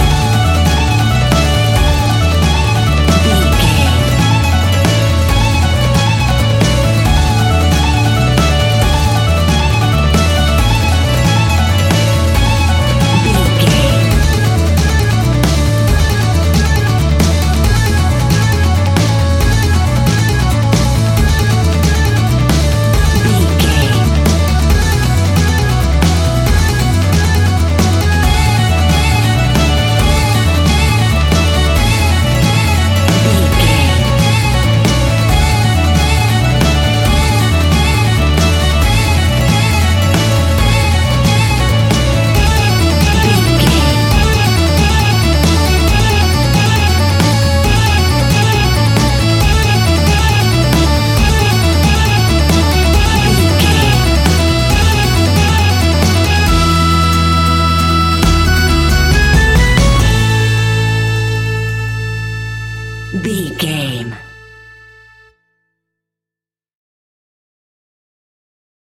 Ionian/Major
acoustic guitar
mandolin
ukulele
lapsteel
drums
double bass
accordion